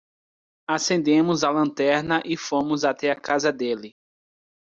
Pronounced as (IPA)
/lɐ̃ˈtɛʁ.nɐ/